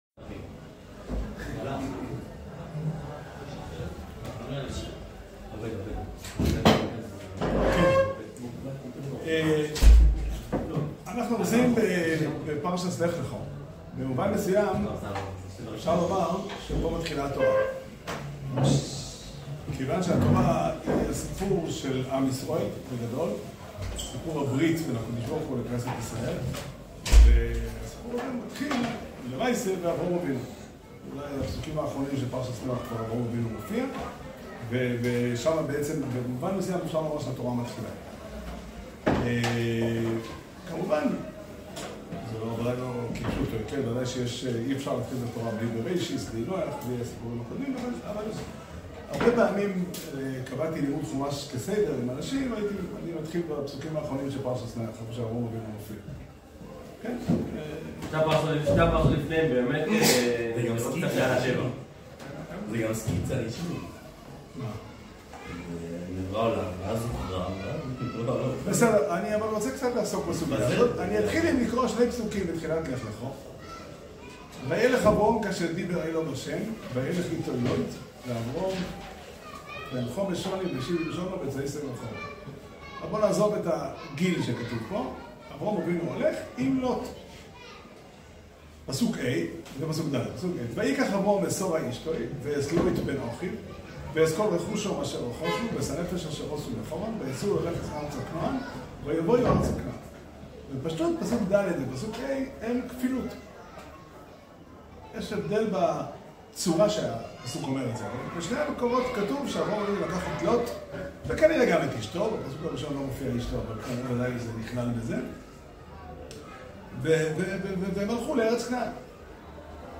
שיעור שנמסר בבית המדרש פתחי עולם בתאריך ג' חשוון תשפ"ה